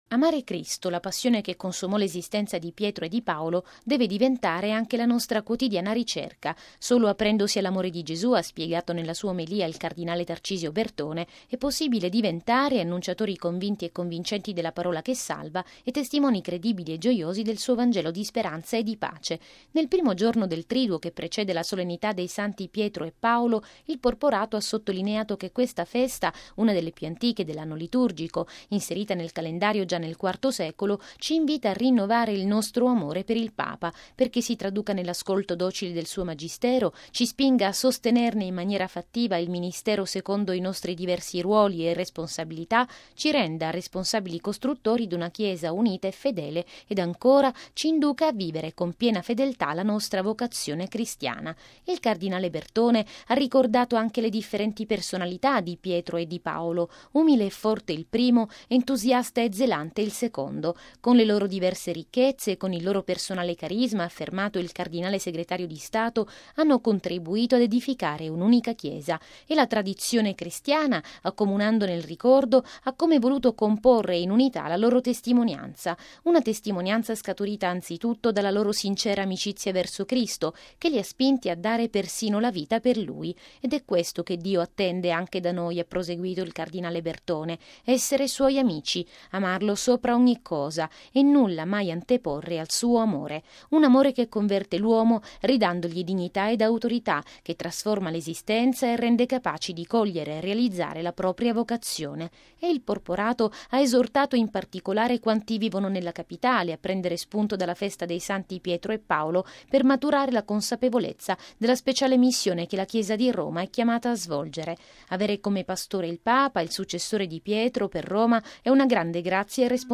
◊   Ieri pomeriggio il cardinale segretario di Stato Tarcisio Bertone ha aperto il Triduo Petro-Paolino nella Basilica romana di San Paolo fuori le Mura. Nella sua omelia, il porporato, che ha presieduto i Vespri e la Messa, ha invitato i fedeli a camminare uniti alla luce degli insegnamenti del Papa.